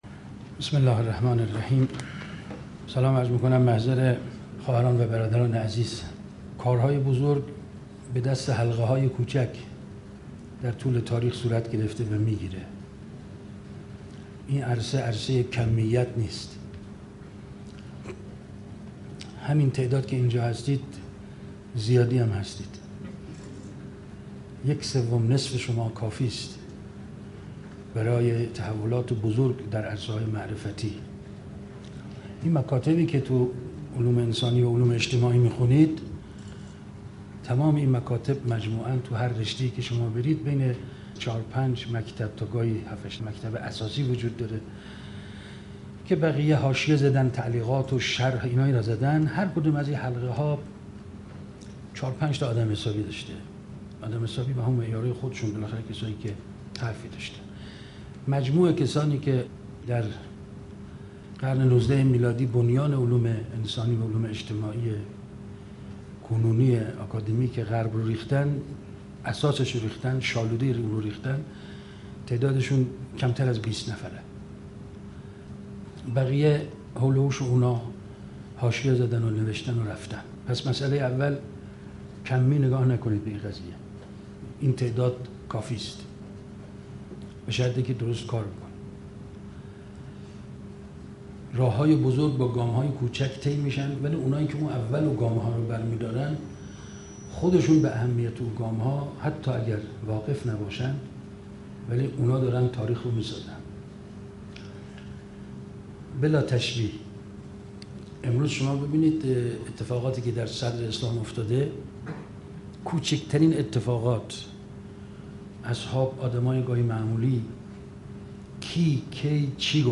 درجمع بسیج دانشجویی _ نشست ( علوم انسانی : خط تولید ، خط ترجمه ) _ ۱۳۹۳